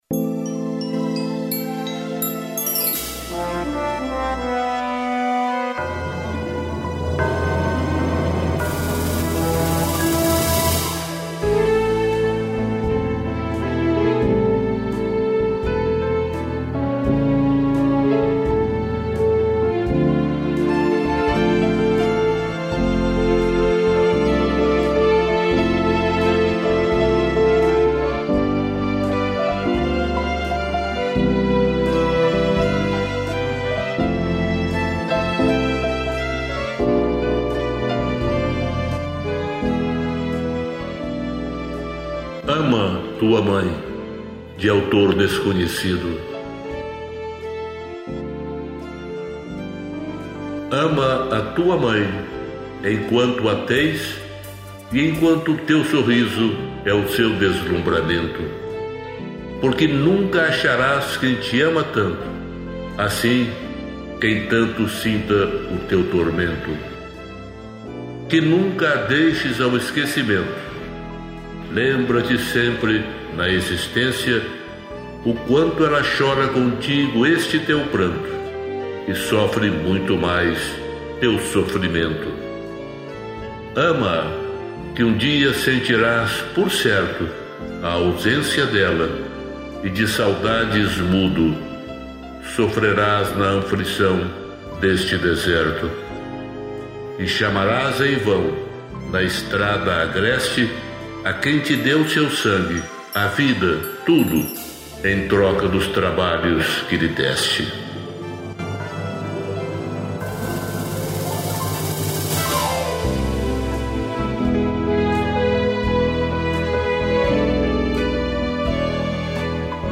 piano, cello e violino